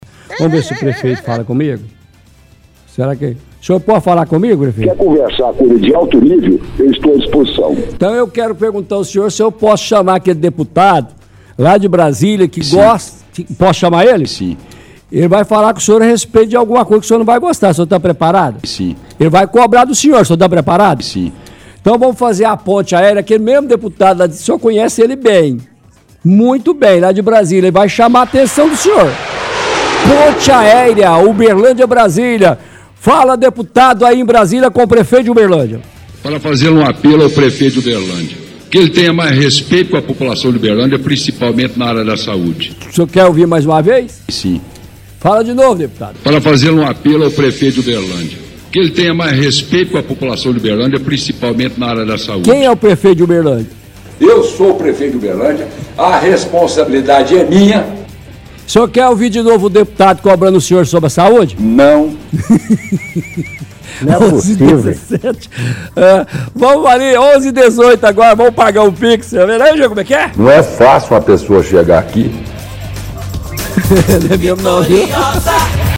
– Transmissão de áudio do Odelmo, enquanto deputado, pedindo para que o prefeito olhe mais para a área da saúde.